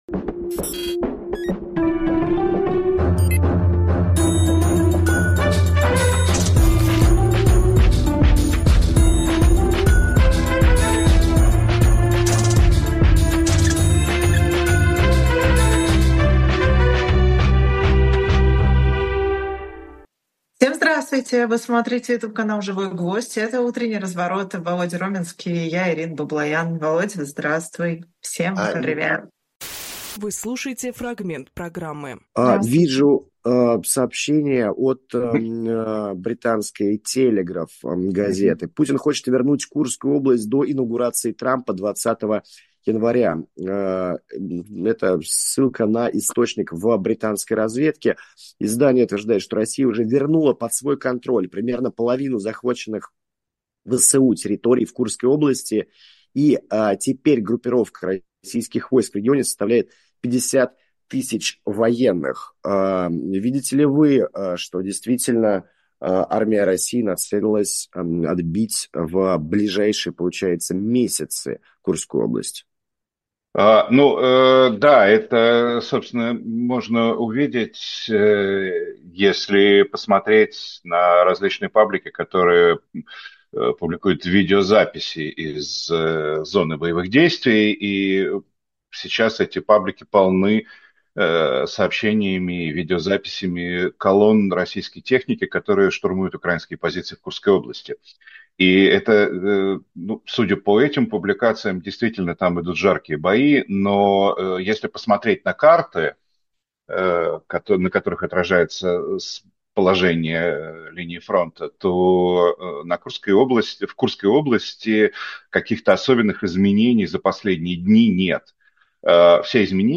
Фрагмент эфира от 12.11.24